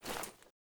m1a1_new_holster.ogg